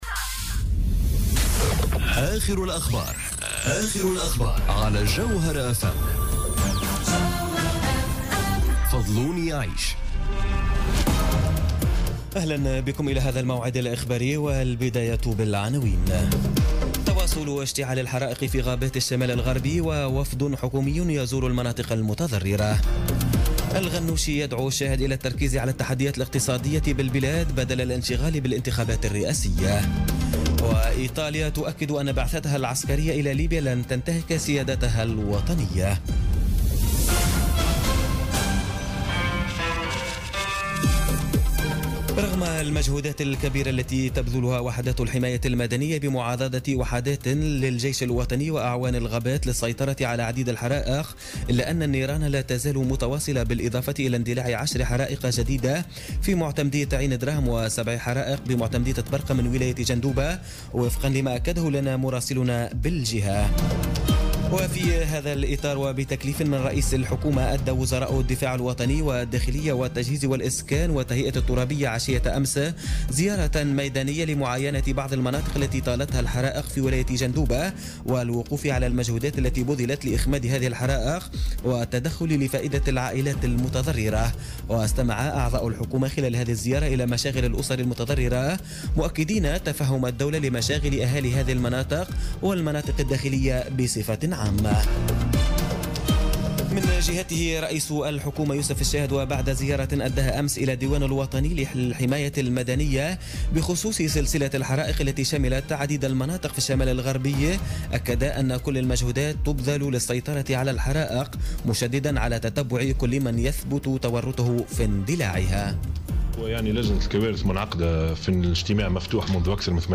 نشرة أخبار منتصف الليل ليوم الاربعاء 2 أوت 2017